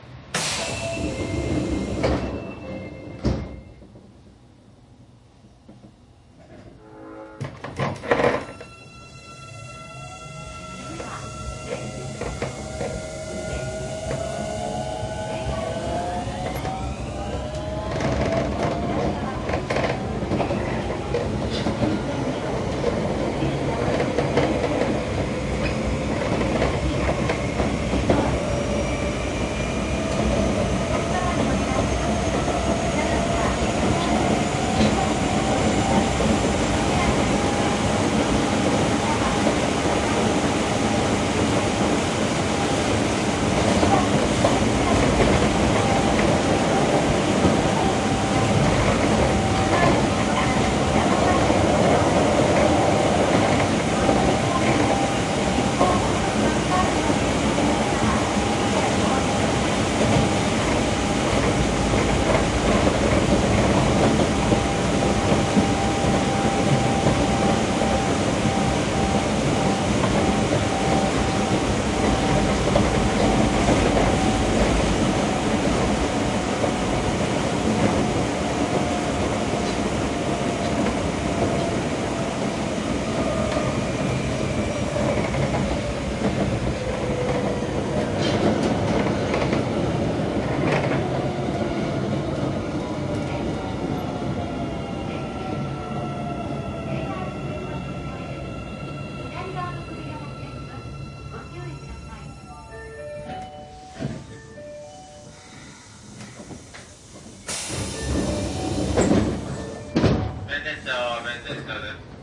走行音(東芝)[osk20isa.mp3/MP3-64kbps Stereo/877KB]
区間：中央線九条→弁天町
日時：2006年1月3日(女声自動放送)
種類：VVVFインバータ制御(日立系IGBT、1C2M×2群)、WN平行カルダン駆動